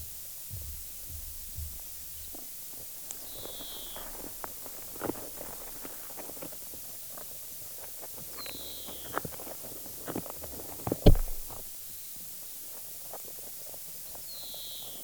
Das wollte ich mit einer Bodensonde der Stiftung Biovision herausfinden. So begab ich mich im Mai 2023 in meine Pünt in Winterthur, um dem Lebewesen zuzuhören, die sich in meinem Gartenboden tummeln. An einem Ort war es besonders laut, an anderen eher leise.